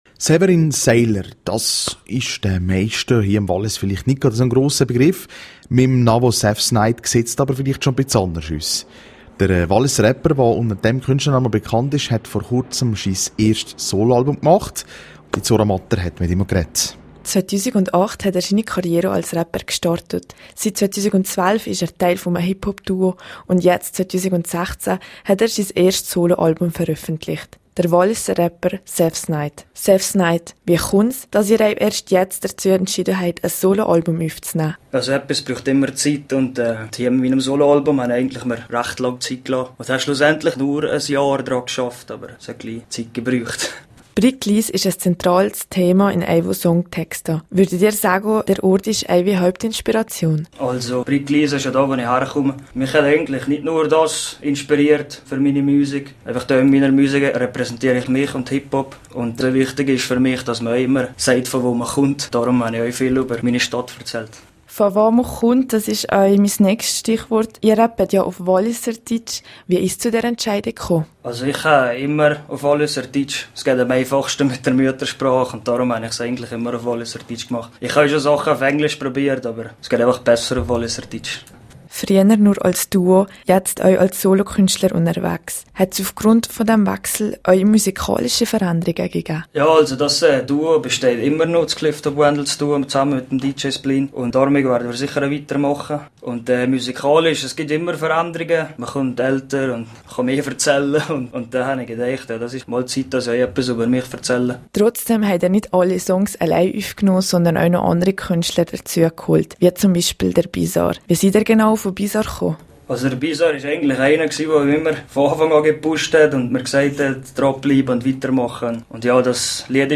16021_News.mp3